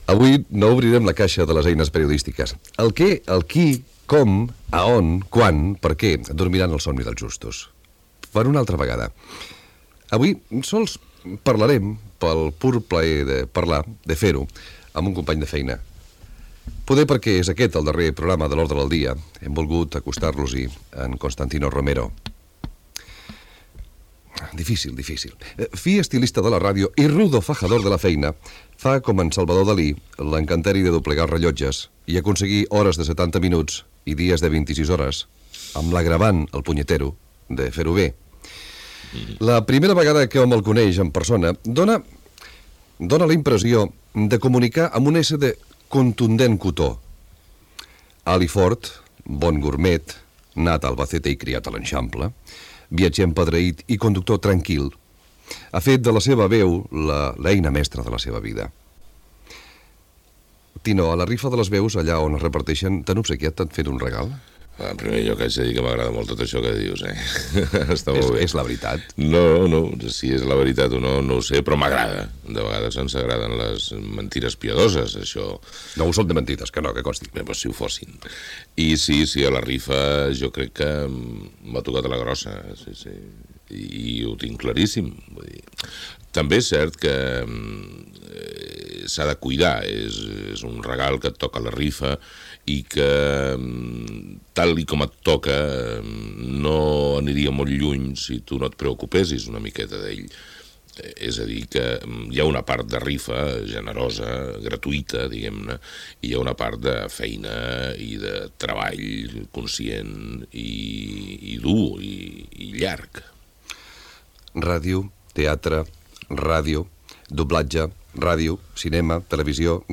589ae32f15fc49fbb8bd4c7738aafefd2b26e81e.mp3 Títol Ràdio 4 Emissora Ràdio 4 Cadena RNE Titularitat Pública estatal Nom programa L'ordre del dia Descripció Últim programa. Presentació i entrevista al presentador Constantino Romero